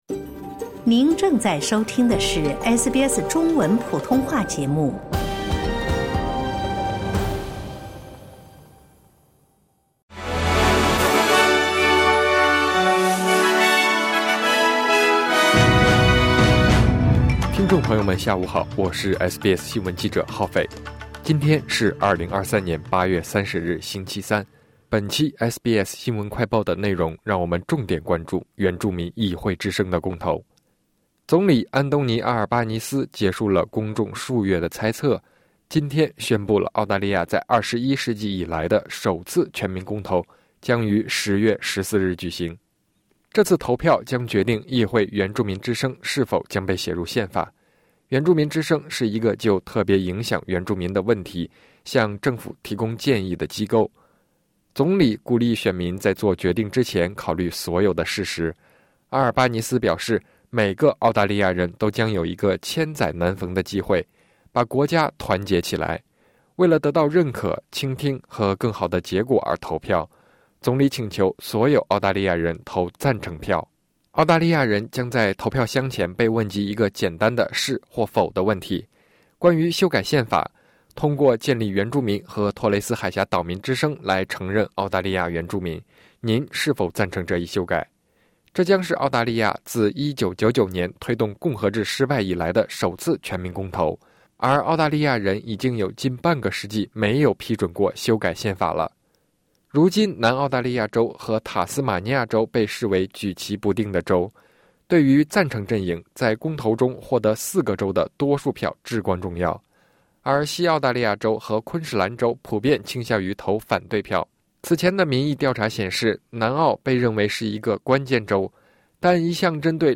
【SBS新闻快报】澳联邦总理宣布原住民议会之声入宪公投日期